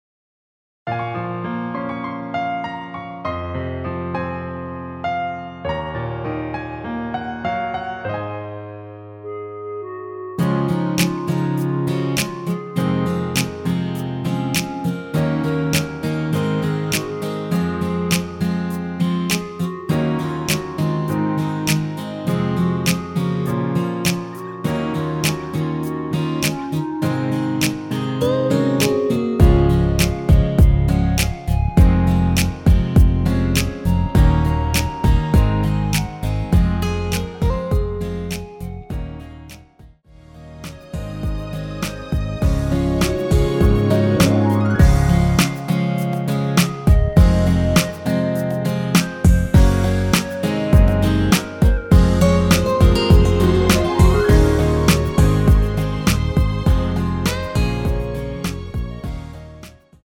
원키에서(-2)내린 멜로디 포함된 편곡 MR 입니다.
Db
앞부분30초, 뒷부분30초씩 편집해서 올려 드리고 있습니다.